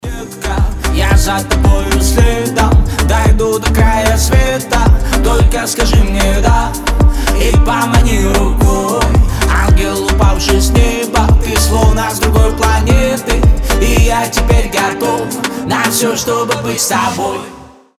• Качество: 320, Stereo
мужской голос
ритмичные
заводные